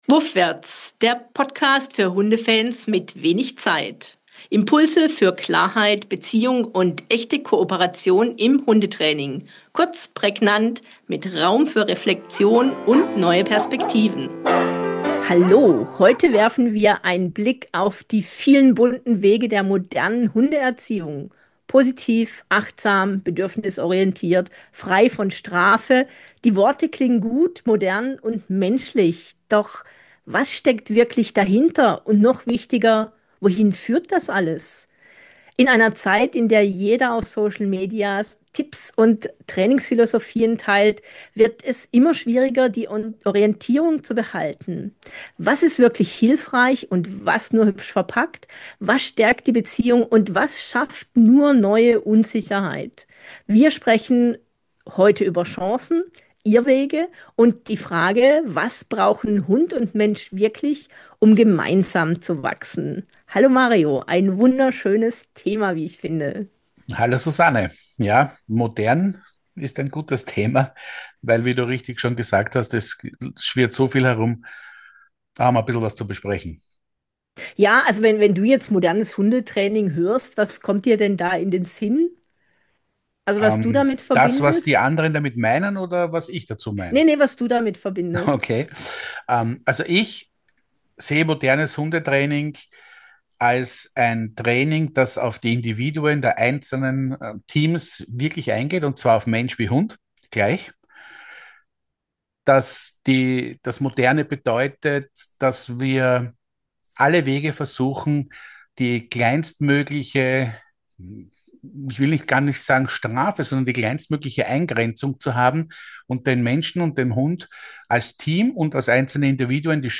In dieser Folge von Wuffwärts sprechen wir über Chancen und Irrwege moderner Erziehungsmethoden. Wir beleuchten, warum Haltung, Herz und Handwerk untrennbar zusammengehören, wie Harmonie und Struktur Sicherheit schaffen und weshalb echte Entwicklung nur gelingt, wenn Mensch und Hund gemeinsam wachsen. Ein Gespräch über Orientierung, Verantwortung und den Mut, hinter schöne Worte zu schauen.